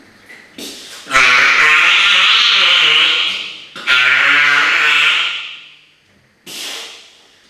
Audio files for the following article: Aerial Vocalizations by Wild and Rehabilitating Mediterranean Monk Seals (Monachus monachus) in Greece
Pup Squawk
pupsquawk.wav